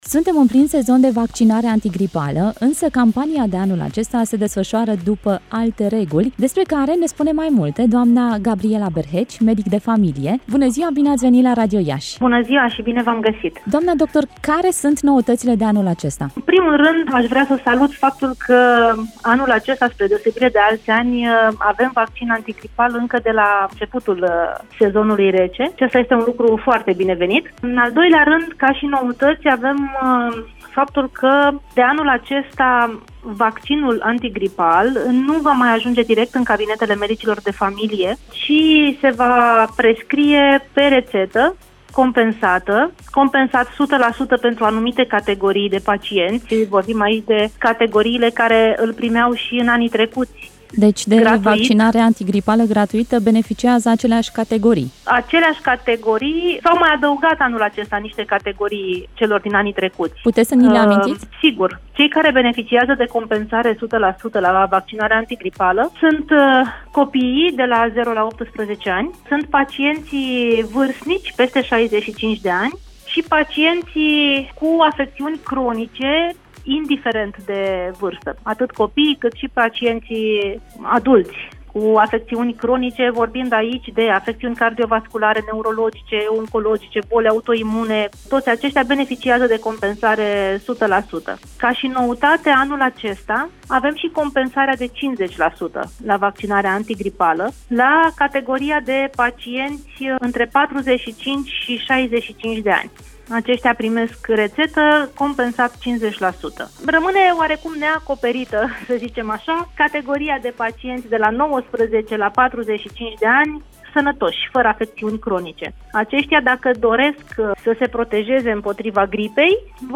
medic de familie.